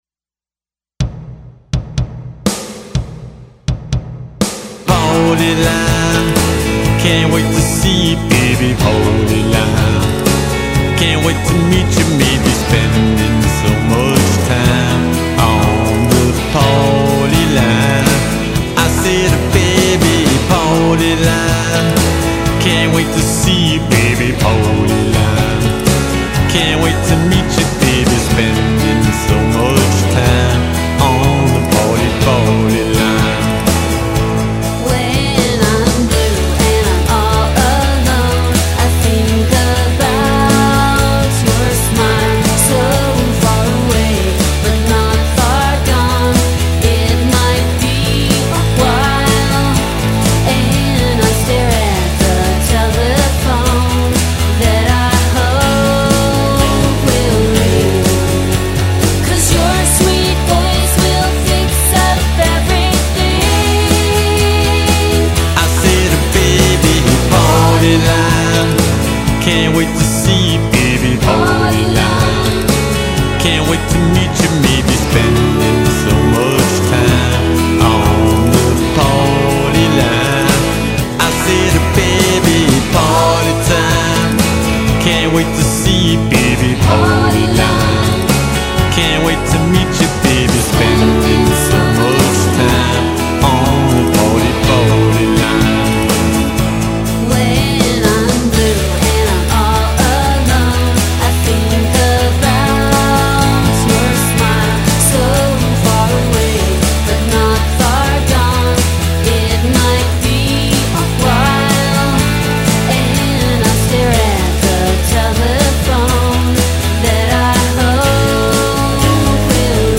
- 2012: Vocals on